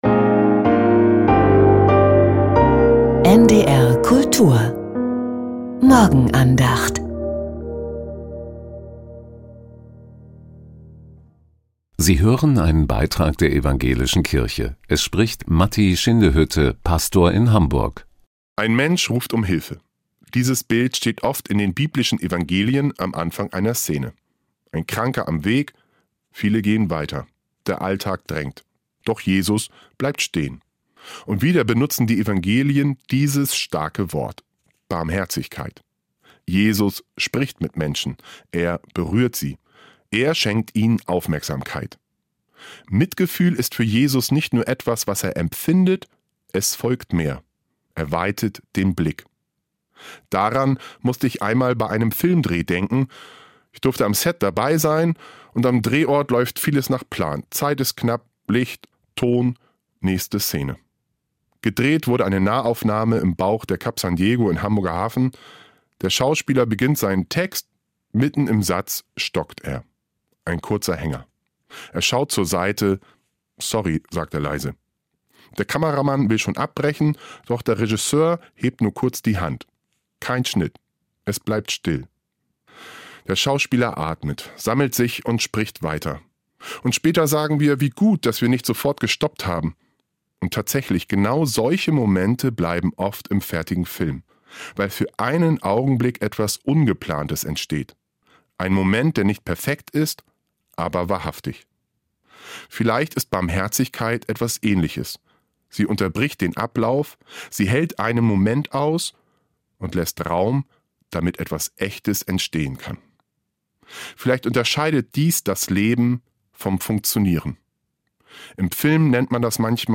Keine großen Worte ~ Die Morgenandacht bei NDR Kultur Podcast